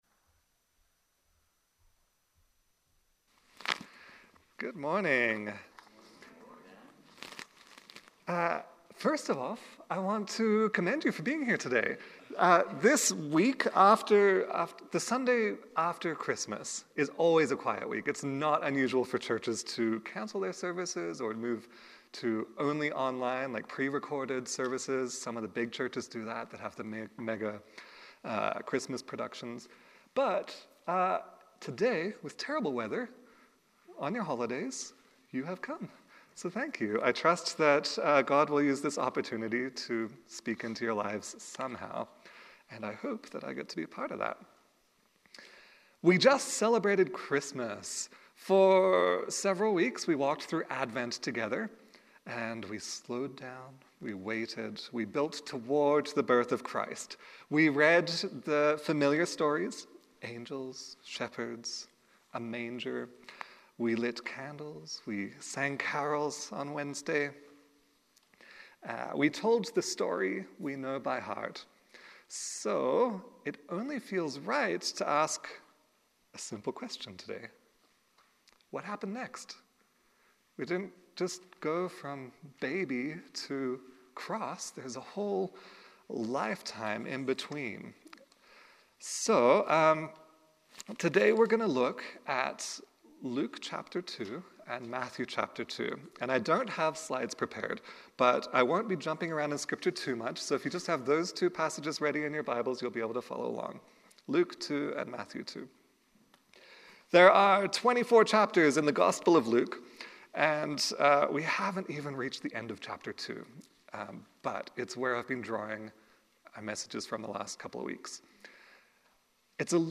Sermons | Clairmont Community Church